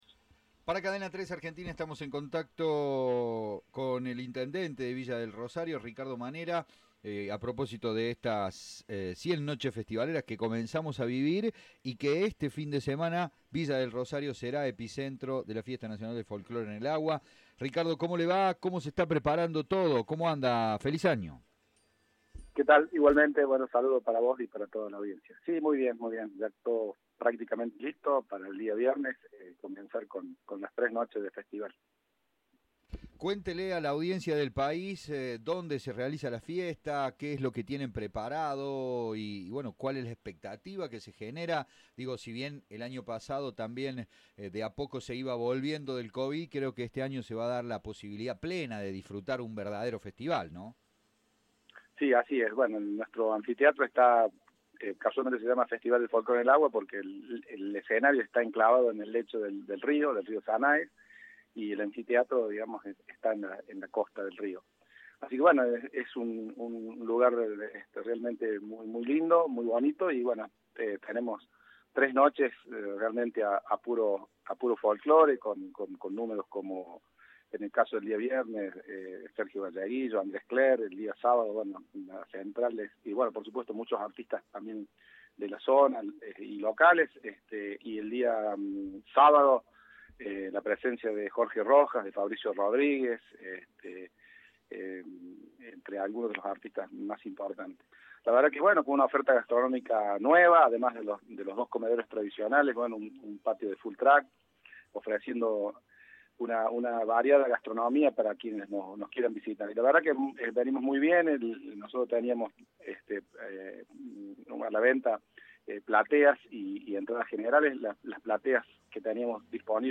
Ricardo Manera, intendente de la ciudad, expresó, en diálogo con Cadena 3, su expectativa por la nueva edición del evento. Será desde el viernes 6 al domingo 8 de enero.